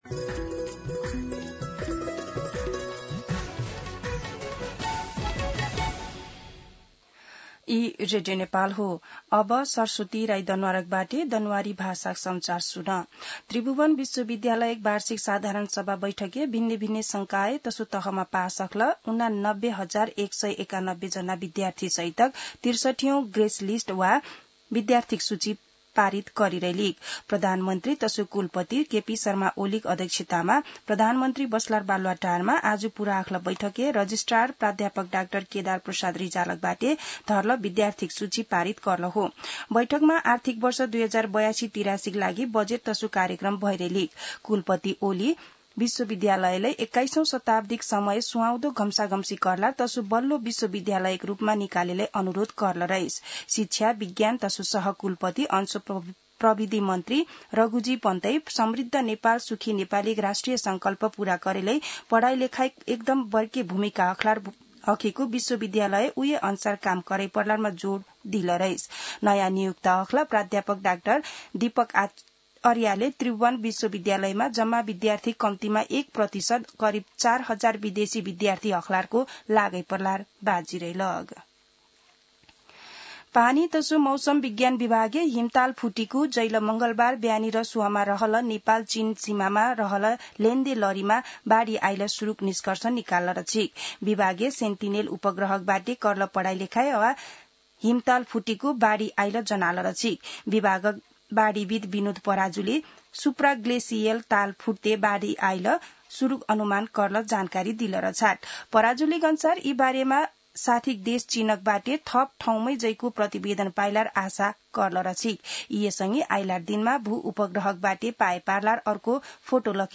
दनुवार भाषामा समाचार : २६ असार , २०८२
Danuwar-News-3-26.mp3